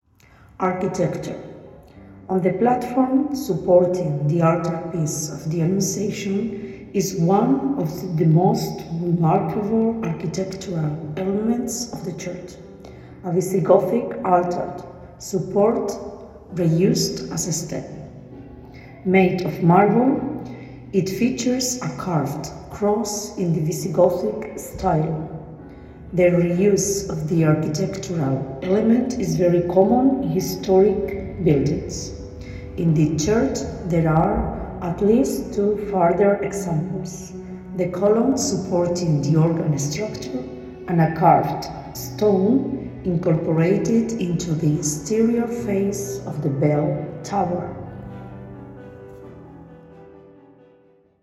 Bloque Principal de la Entidad Ayuntamiento de Segura de León .arquitectura2 Arquitectura / Architecture usted está en Capilla del Rosario / Chapel of the Rosary » Arquitectura / Architecture Para mejor uso y disfrute colóquese los auriculares y prueba esta experiencia de sonido envolvente con tecnología 8D.